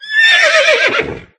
Horse.ogg